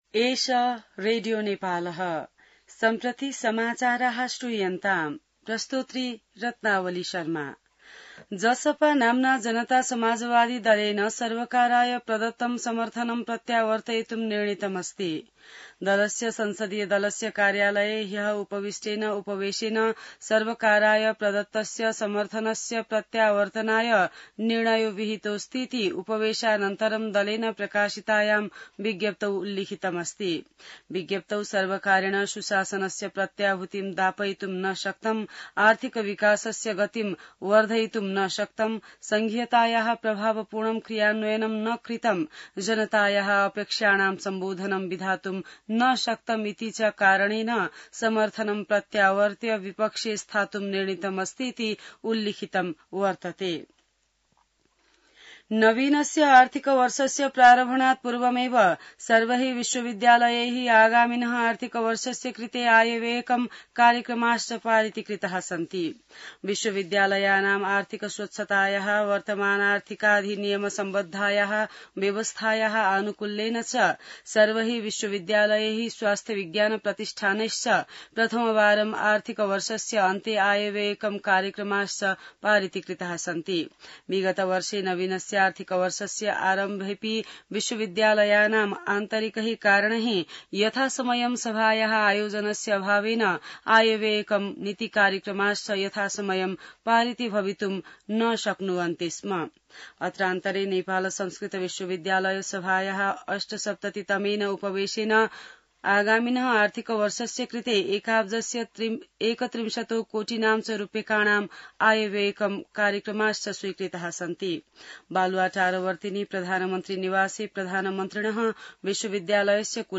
An online outlet of Nepal's national radio broadcaster
संस्कृत समाचार : १ साउन , २०८२